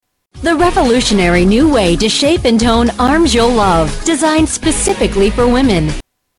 Tags: Shake Weight for Women Shake Weight for Women clips Shake Weight for Women commercial Shake Weight Shake Weight clips